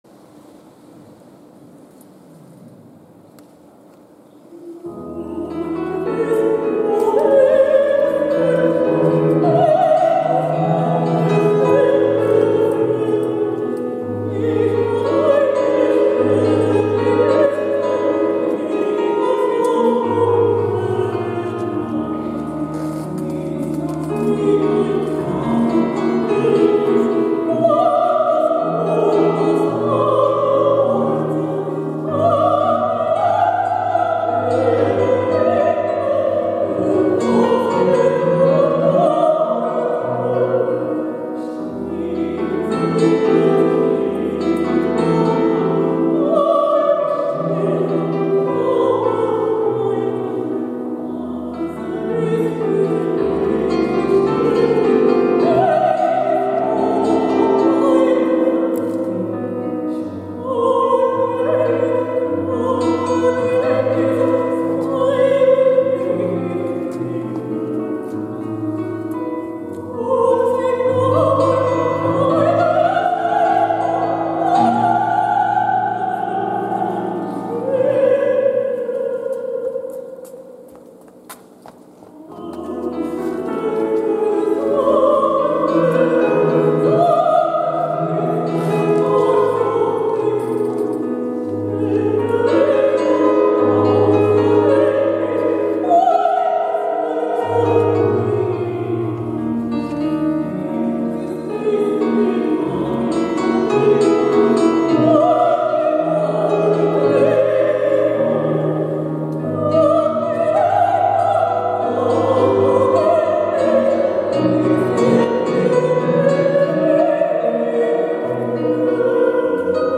Actuació musical: Unendlich!, F. Nietzsche. 7 Lieder Nº4
en l'acte d'homenatge a Josep Maria Terricabras
a l’Aula Magna Modest Prats de l'edifici de Sant Domènec, al campus de Barri Vell de la Universitat de Girona